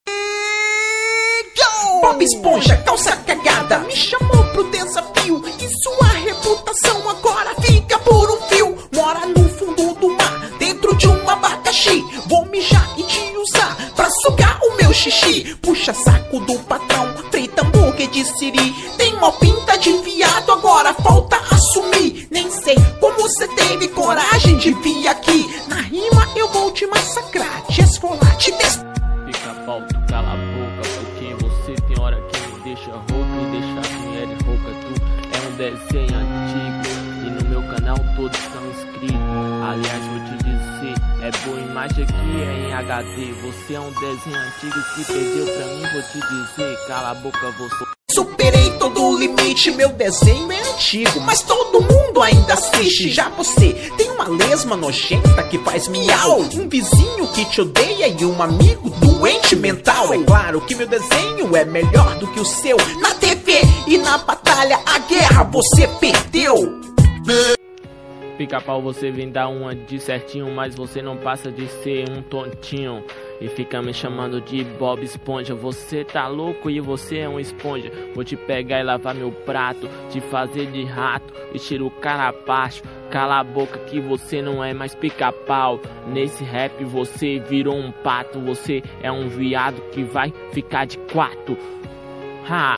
é uma música